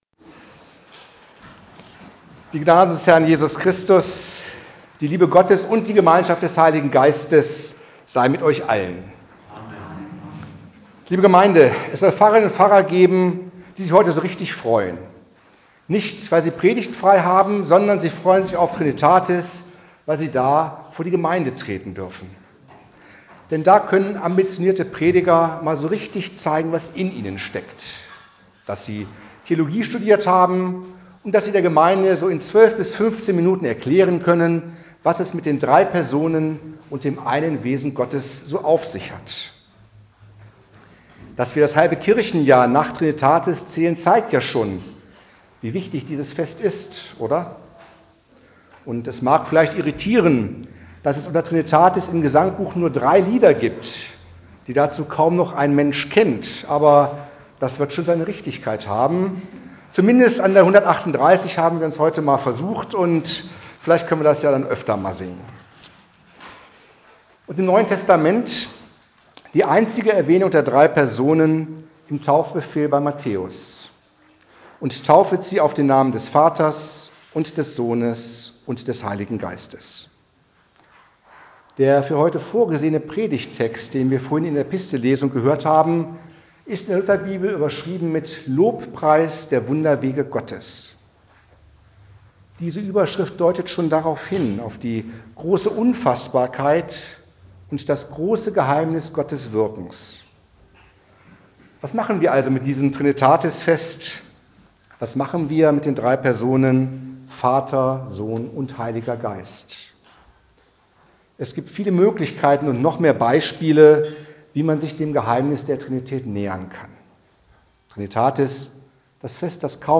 Trinitatispredigt der Petrusgemeinde Börßum
Die Predigt (mit musikalischem Nachspiel) -> zum Nachhören .